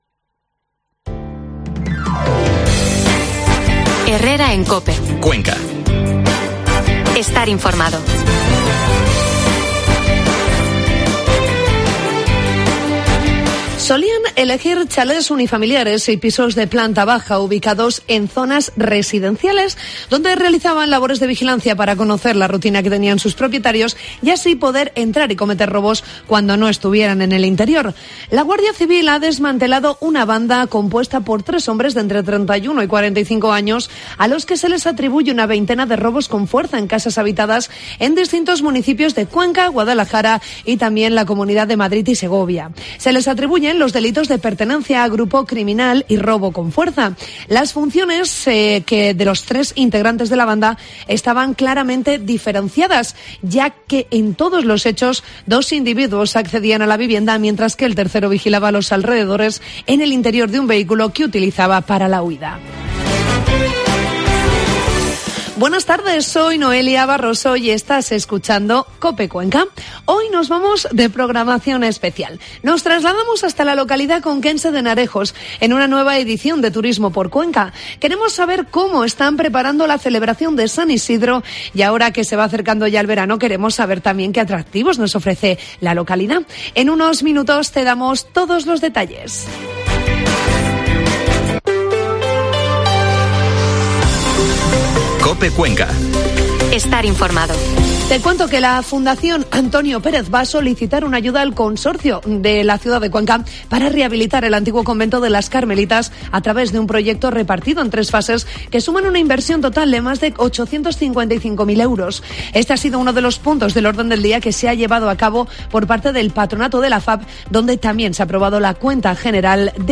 AUDIO: Programa especial de COPE Cuenca desde Henarejos